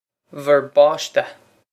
vur bawsh-teh
This is an approximate phonetic pronunciation of the phrase.